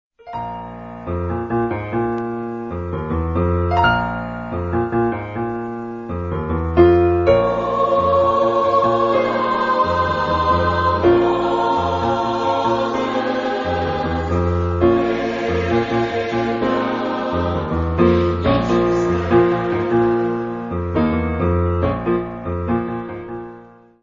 Genre-Style-Forme : Spiritual Afro-Américain ; Sacré
Type de choeur : SAB  (3 voix )
Instrumentation : Piano  (1 partie(s) instrumentale(s))